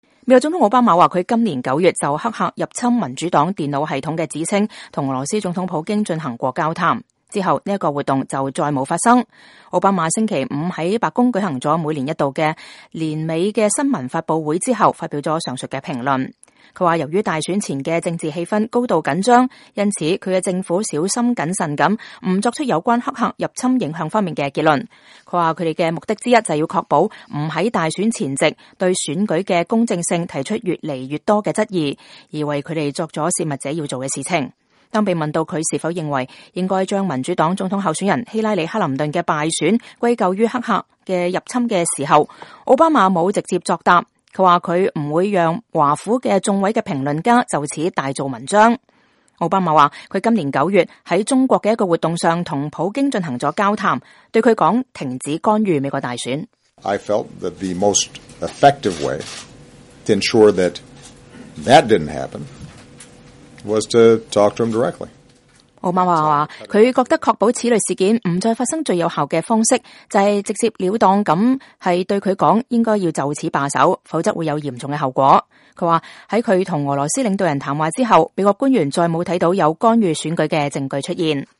奧巴馬總統在白宮新聞發布會上講話（2016年12月16日）